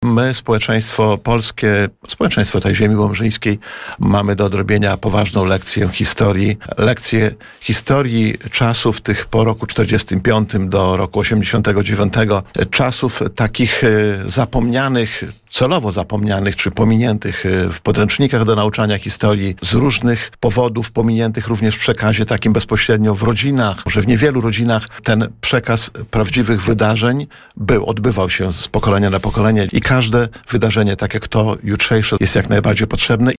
Starosta łomżyński Lech Szabłowski mówił na naszej antenie, że jest to piękna i potrzebna inicjatywa fundacji Iskra Nadziei.